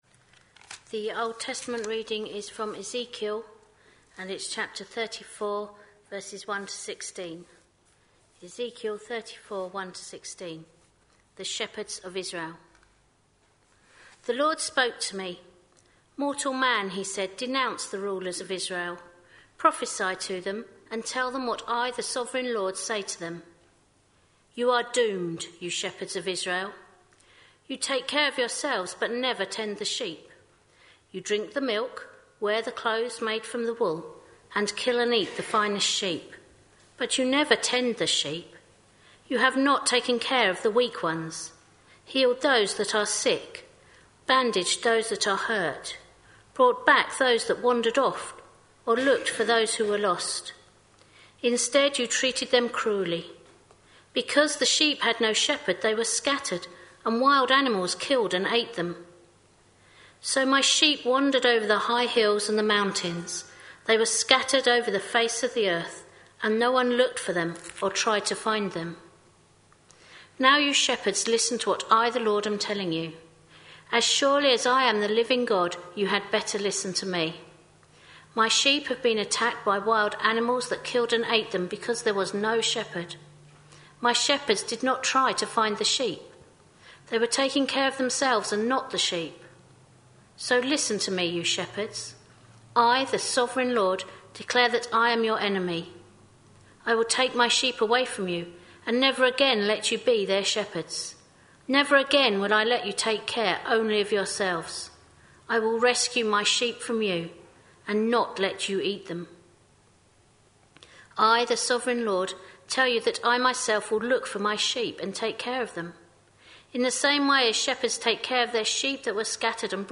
A sermon preached on 16th February, 2014, as part of our Stories with Intent series.